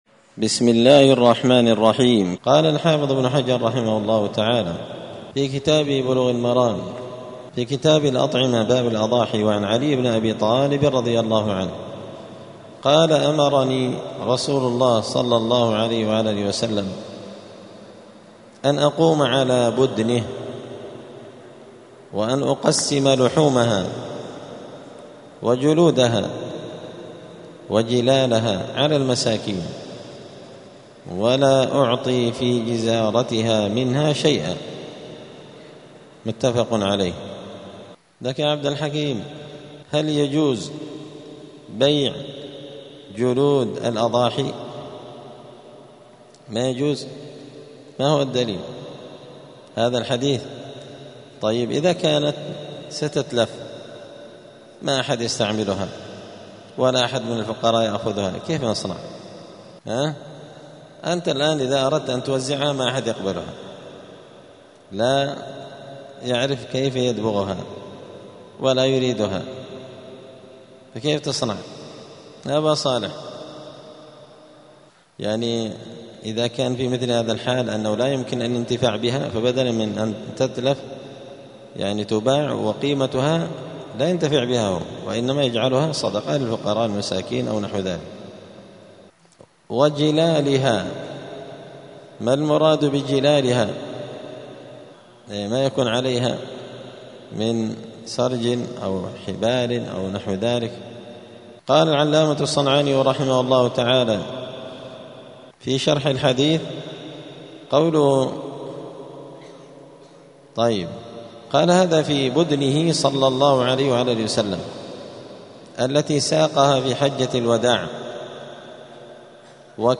*الدرس السادس والعشرون (26) {ﻻ ﻳﻌﻄﻰ اﻟﺠﺰاﺭ ﻣﻦ اﻷﺿﺤﻴﺔ}*
دار الحديث السلفية بمسجد الفرقان قشن المهرة اليمن